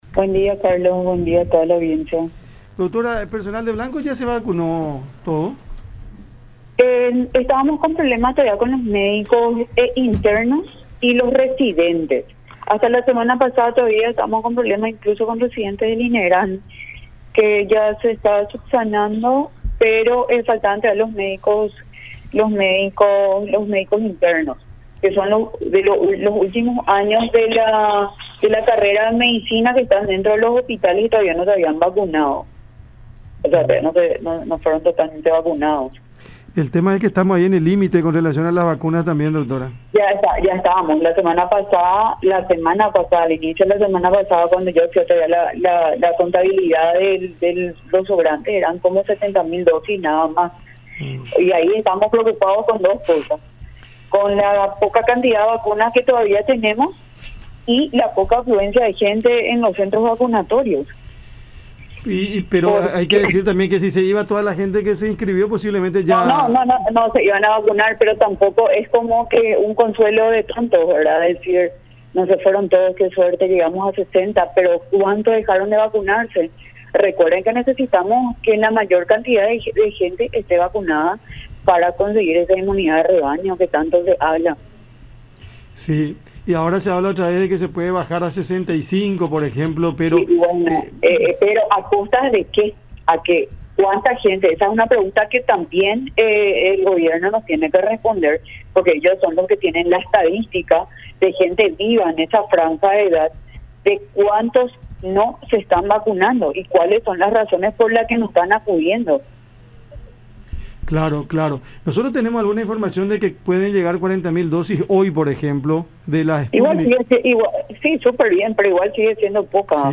en diálogo con Cada Mañana a través de La Unión.